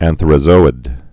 (ănthər-ə-zōĭd)